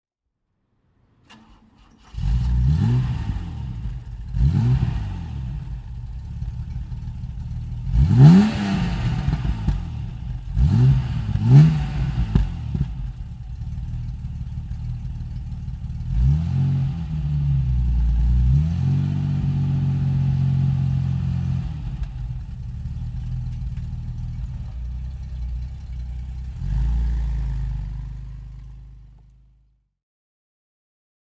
Bitter SC 3.0 (1985) - Starten und Leerlauf